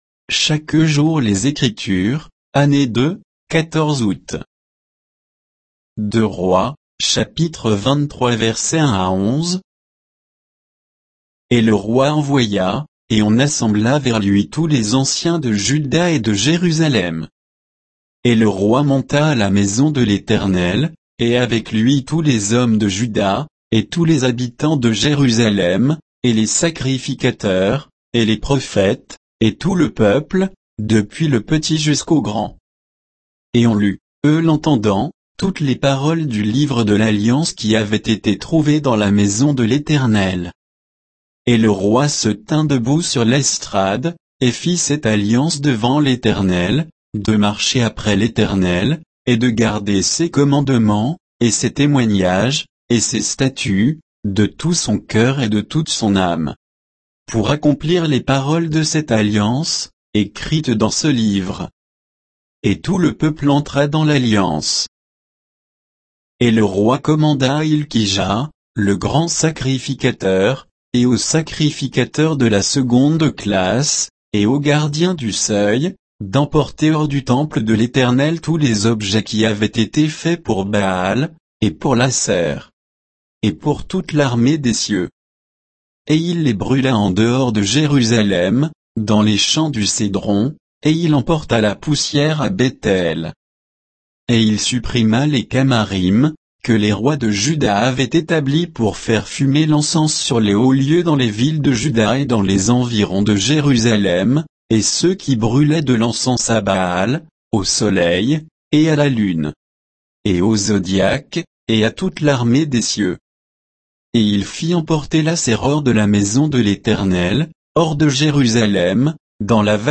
Méditation quoditienne de Chaque jour les Écritures sur 2 Rois 23, 1 à 11